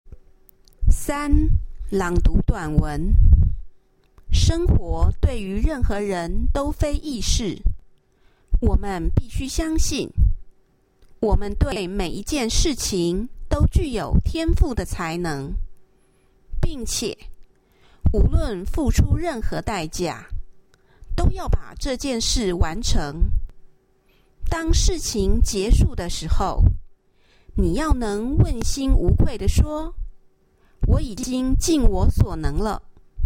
Task 3 Passage Reading
Taiwan Sample: